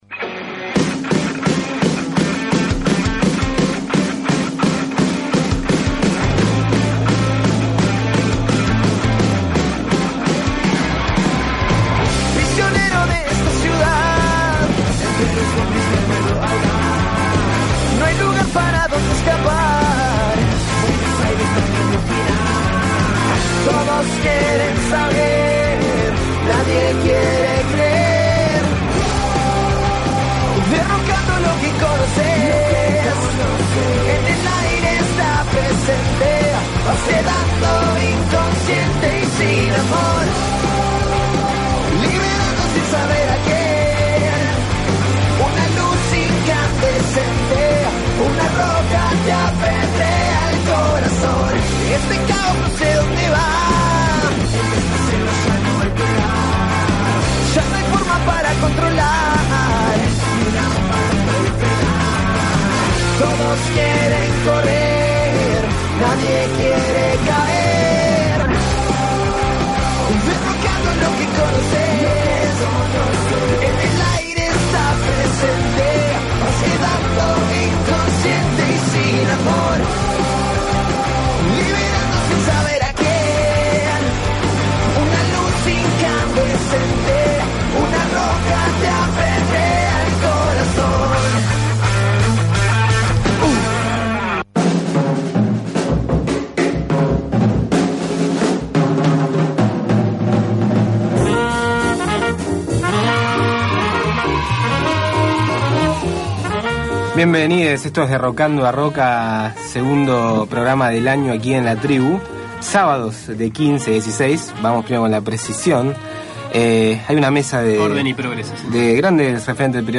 _ Nota y acústico con Club Artístico Libertad. Atravesados por la efeméride que recuerda el fin de la Guerra Civil Española y por el día del exiliado español, repasamos un poco del repertorio de esta banda que recupera canciones populares en la época de guerra civil.